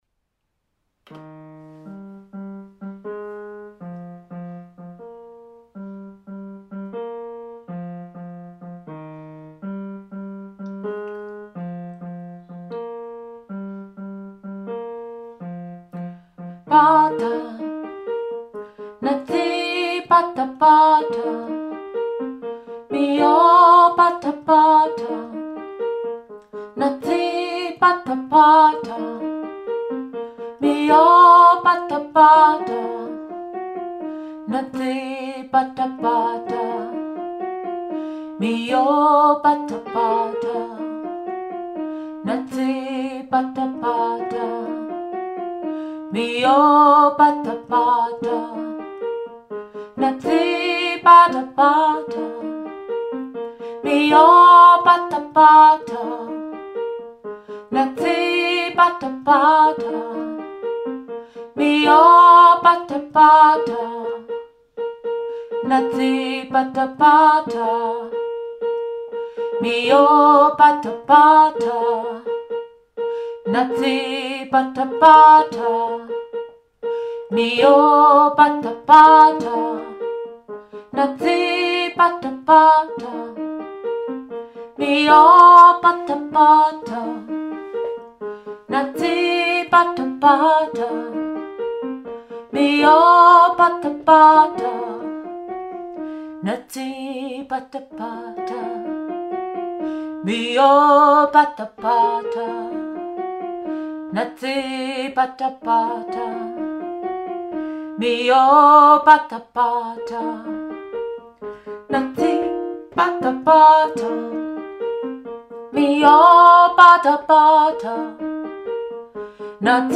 Pata-Pata-Alt2.mp3